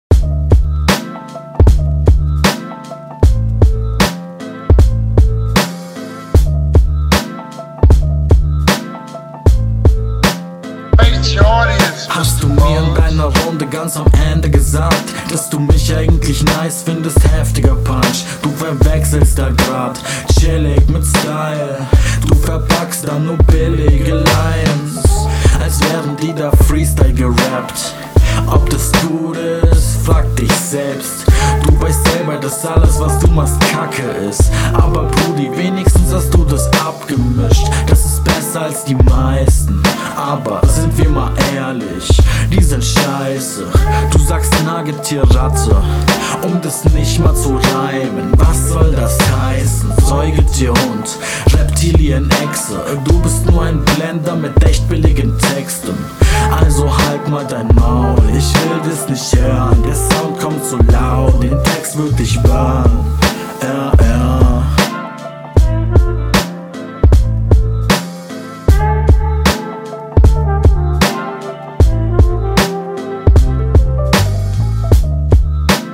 Flowlich deutlich schlechter als die Hinrunde.